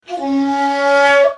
Shakuhachi 54